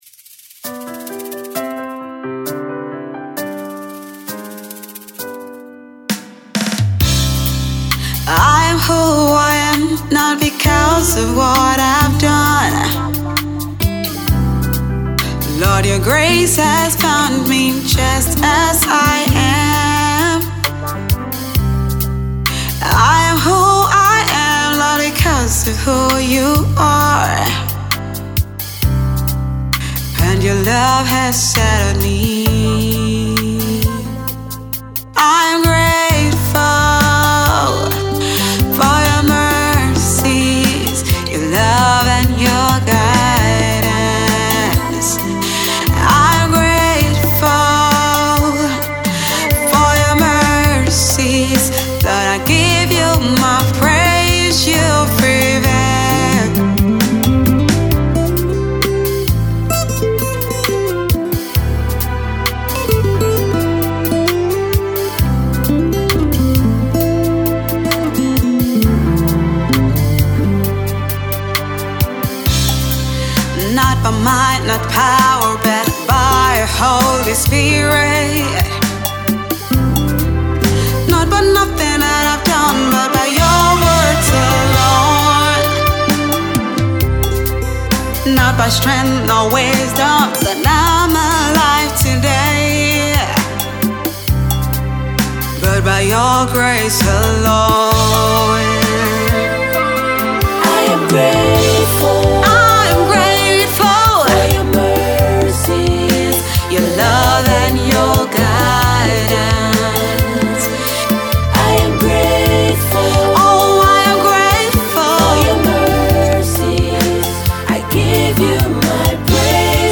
Another budding Ghanaian gospel music sensation